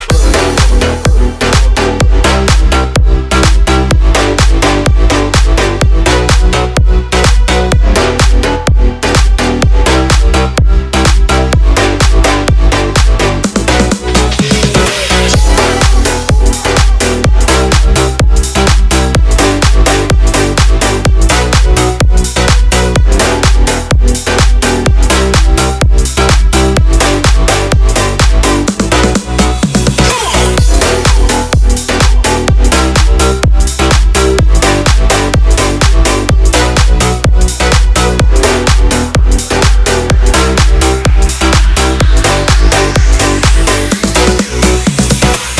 • Качество: 320, Stereo
громкие
Club House
без слов
future house
энергичные